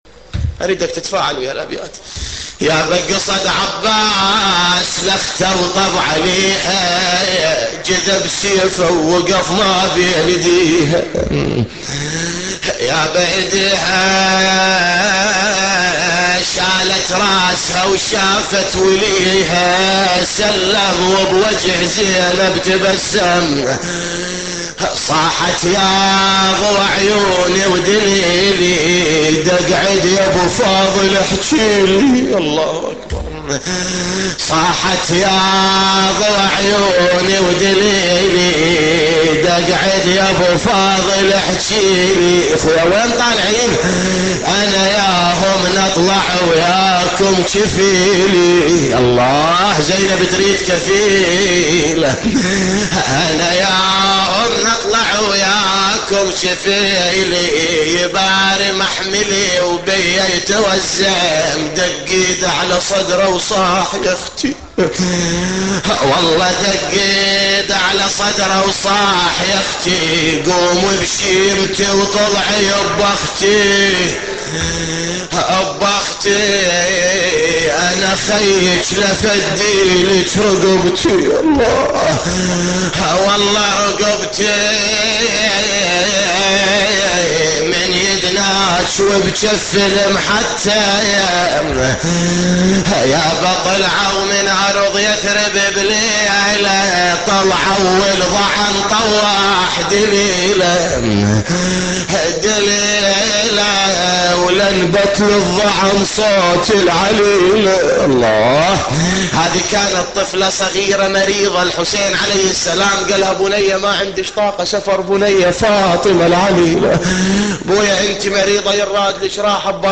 نواعي حسينية